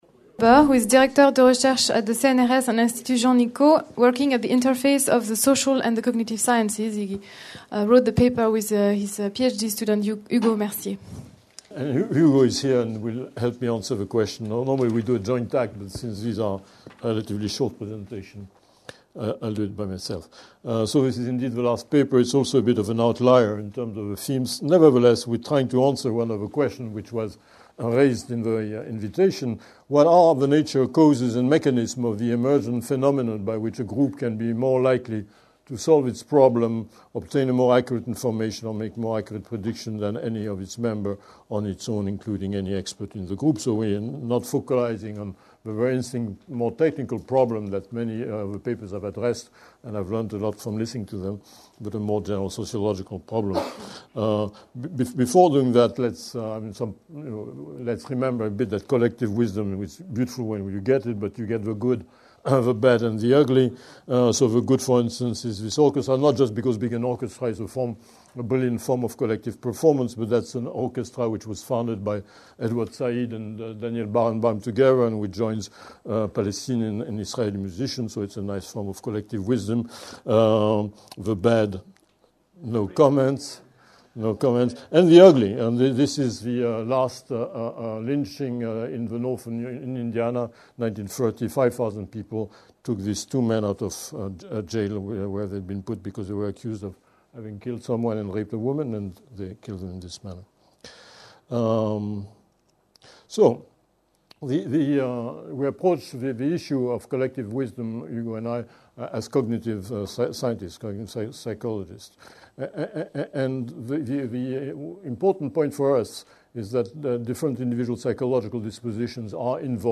La sagesse collective : principes et mécanismes Colloque des 22-23 mai 2008, organisé par l'Institut du Monde Contemporain du Collège de France, sous la direction du Professeur Jon Elster.